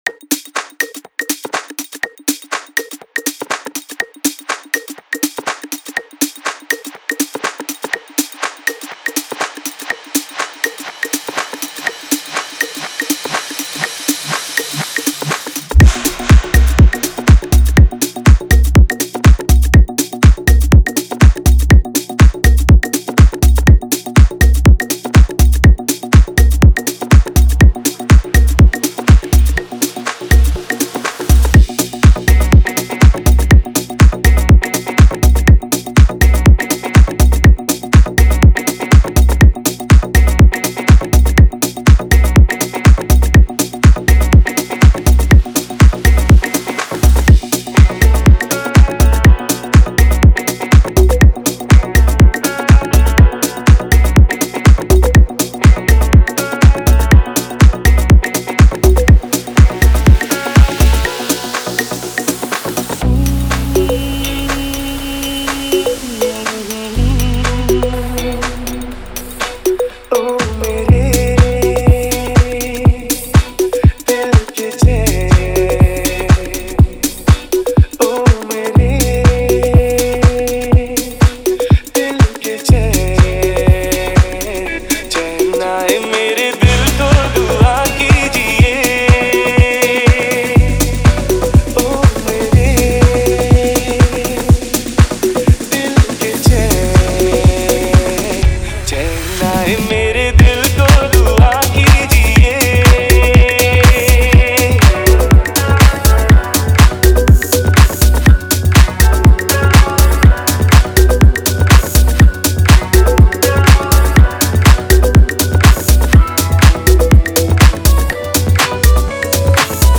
Old Hindi DJ Remix Songs